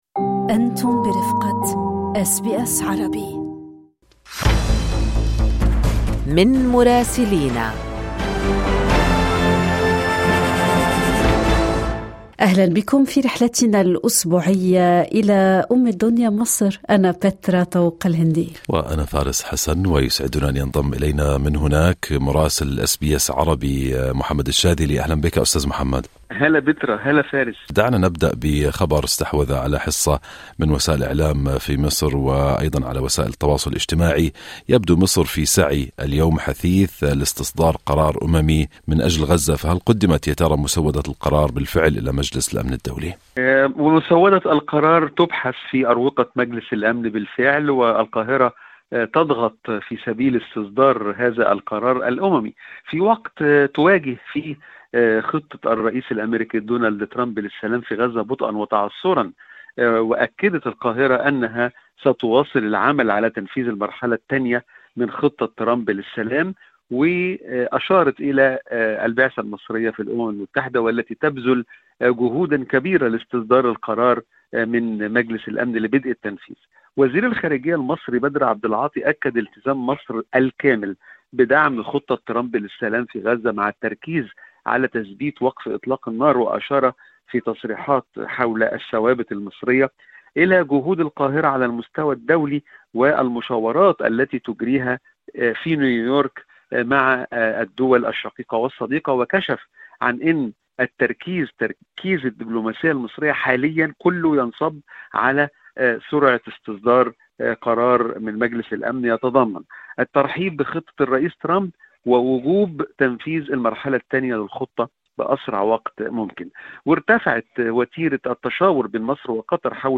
للاستماع إلى أحدث التقارير الصوتية والبودكاست، اضغطوا على الرابط التالي.